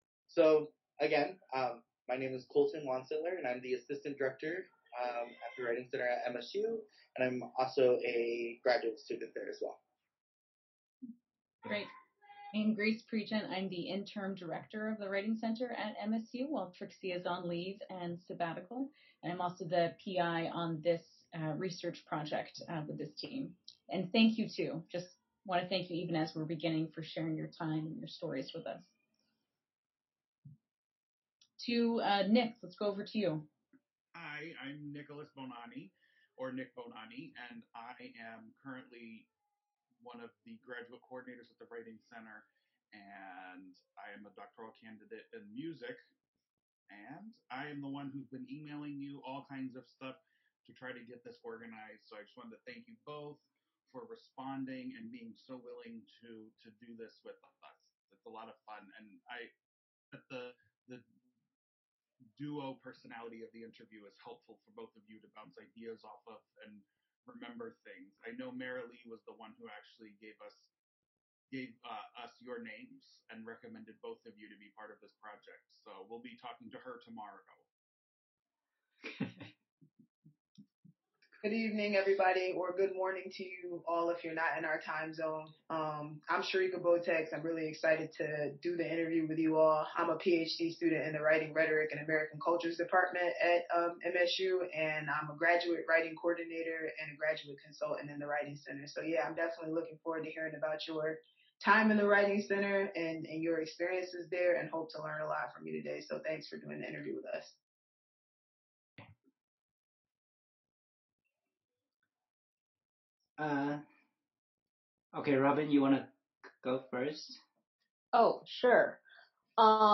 The Writing Center at Michigan State University’s Oral History Research Project